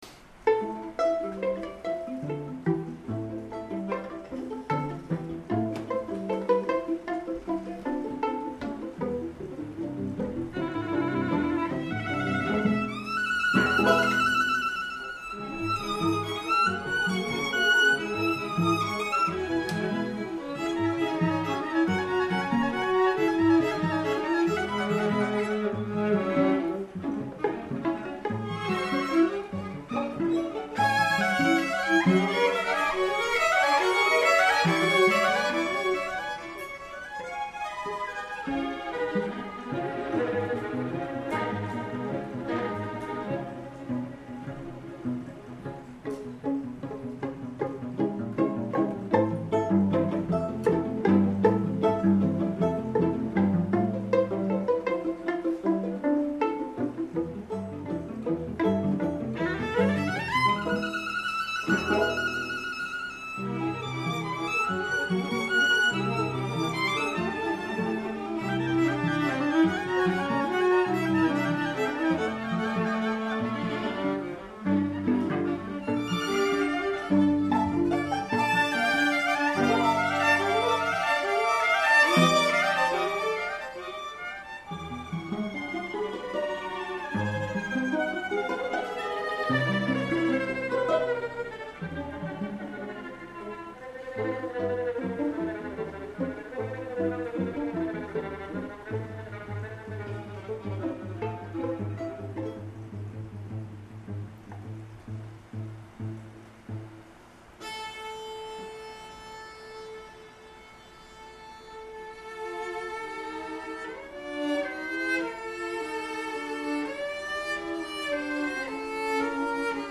2:00 PM on July 28, 2012, South Bay Church
Ravel Quartet in F major
Assez vif. Tre`s rythme'